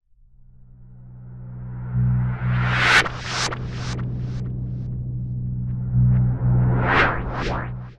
描述：fx循环
Tag: 120 bpm Weird Loops Fx Loops 1.35 MB wav Key : E